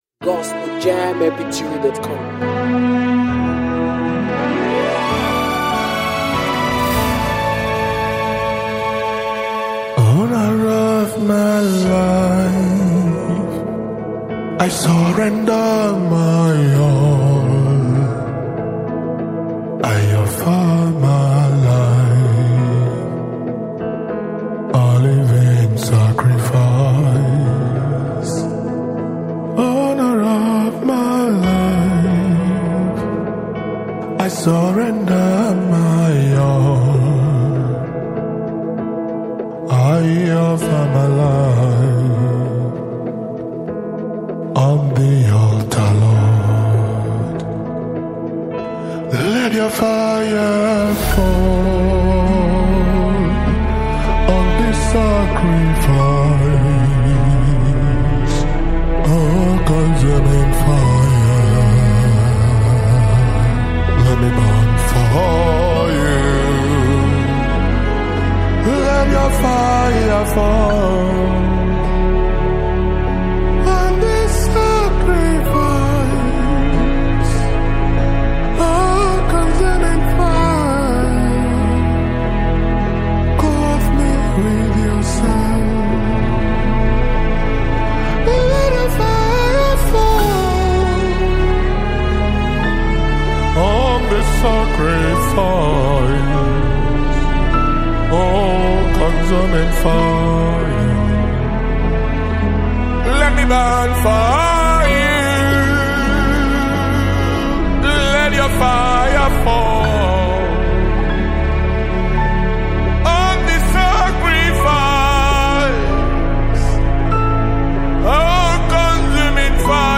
Nigerian gospel worship song
The track carries an atmosphere of intense prayer
passionate vocal delivery
worship anthem